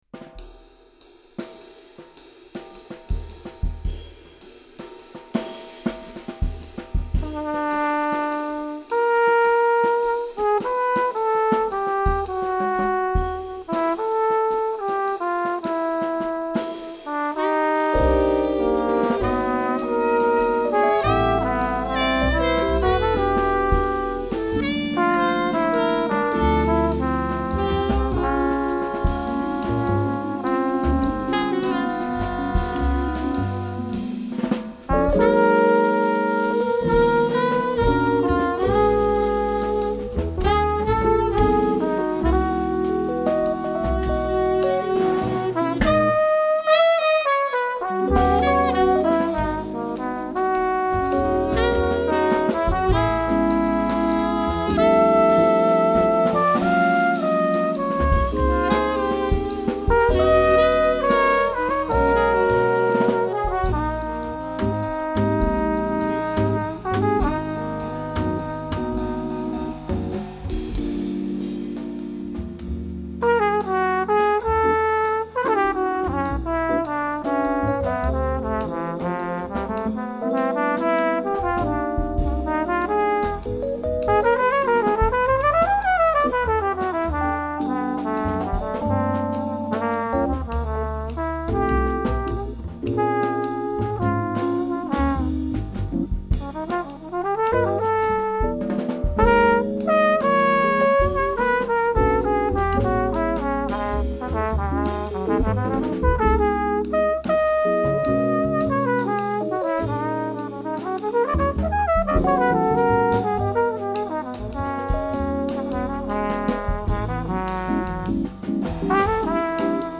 flugelhorn.
piano
alto saxophone
drums
bass.